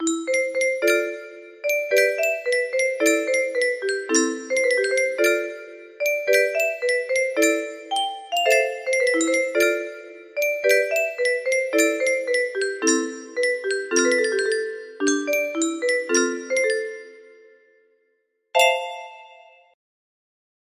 TKT music box melody